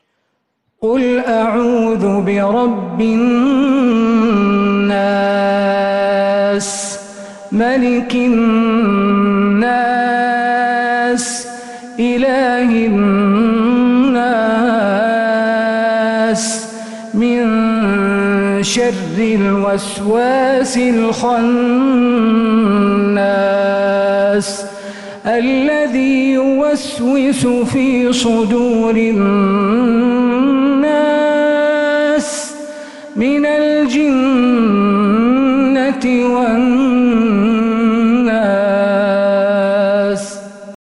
من الحرم النبوي